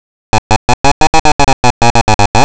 Toledo -- Frases interrogativas sin que -- expansión de objeto (S+V+O+EXP_O)